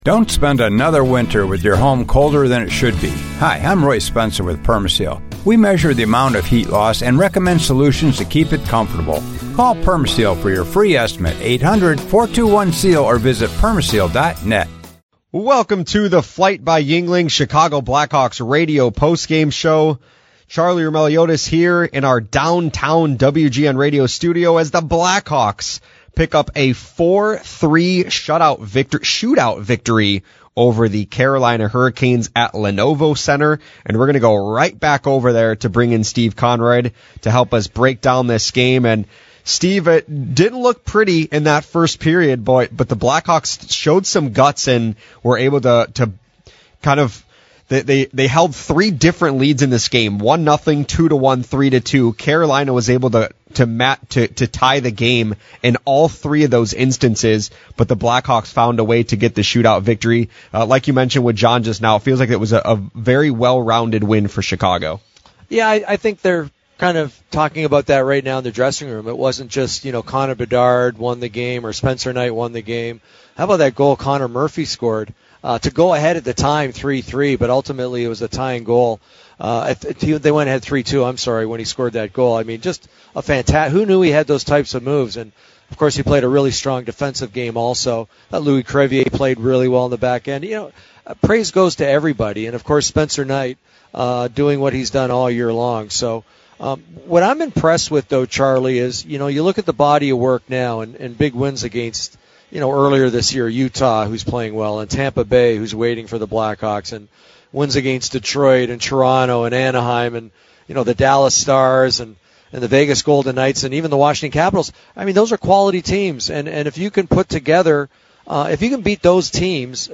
Later, we hear from Moore, Frank Nazar and head coach Jeff Blashill.